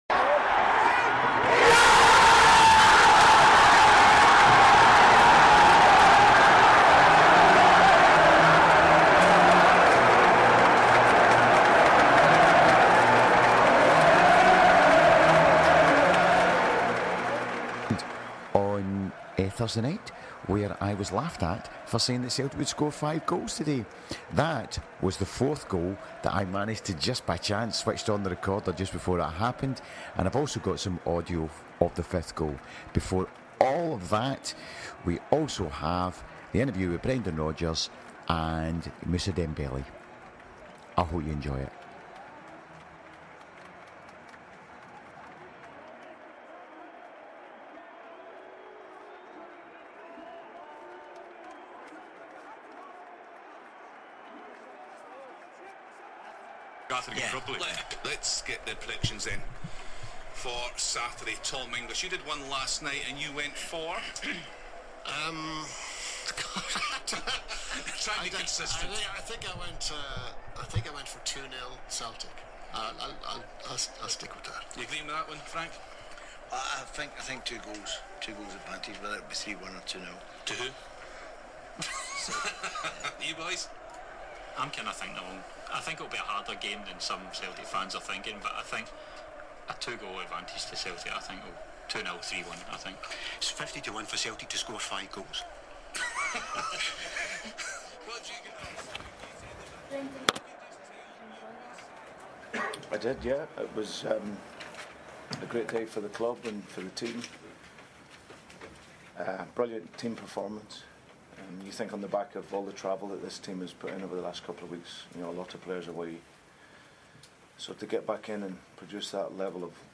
This is the audio from the 5-1 game. I picked up celebrations of both the 4th & 5th goals and sandwiched between is my prediction of 5 goals on Sportsound and interviews with Brendan and Dembele.